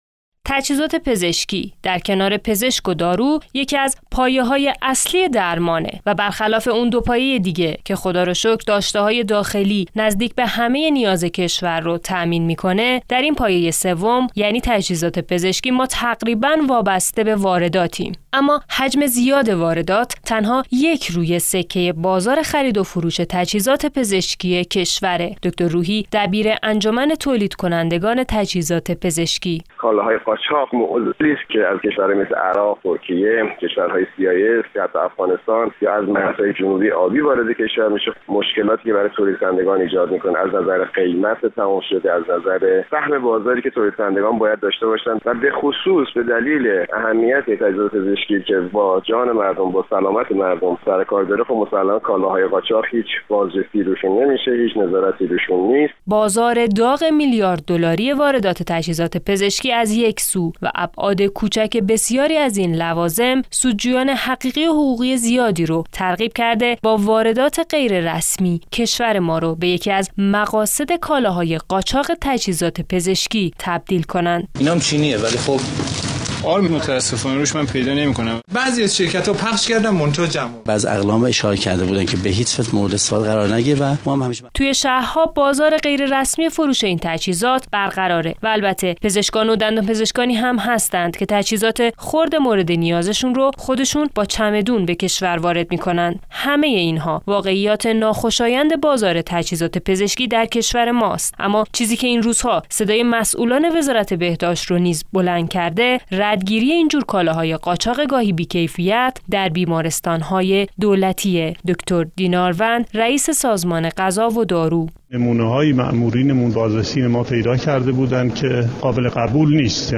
گزارش "شنیدنی"؛ تجهیزات قاچاق در بیمارستانهای دولتی - تسنیم